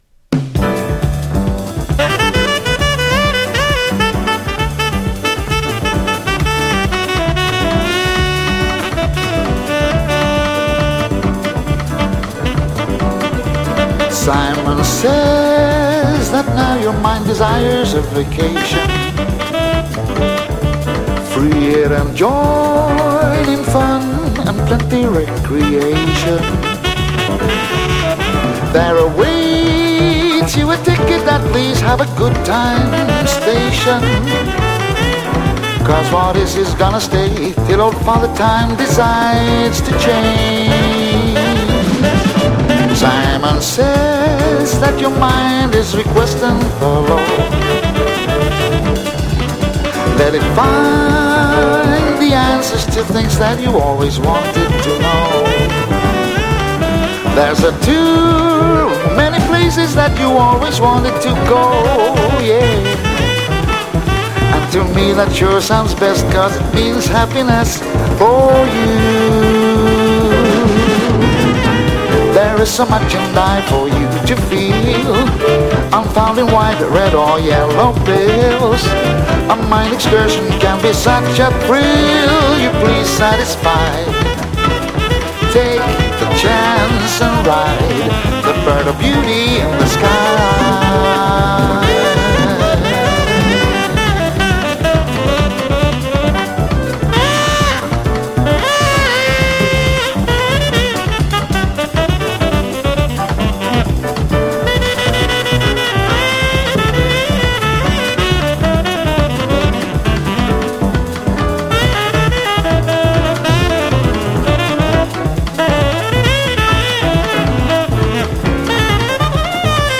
Jazz Vocal sweden